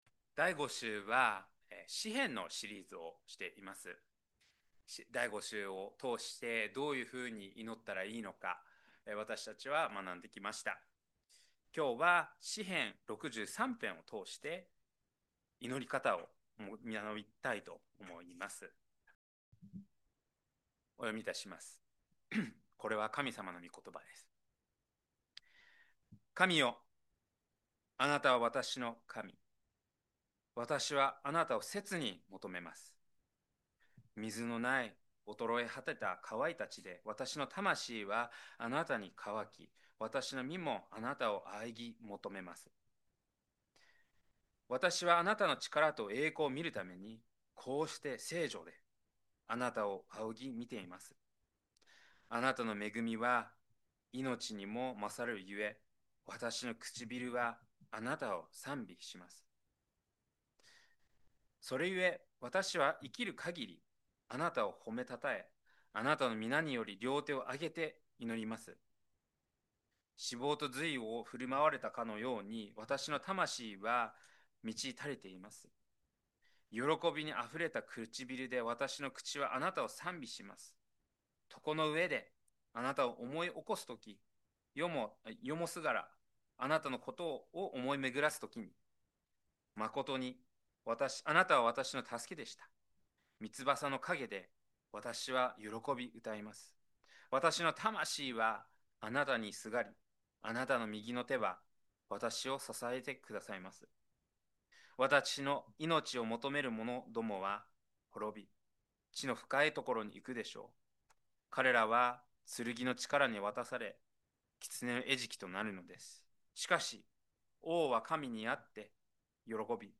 2025年11月30日礼拝 説教 「荒野の日の祈り」 – 海浜幕張めぐみ教会 – Kaihin Makuhari Grace Church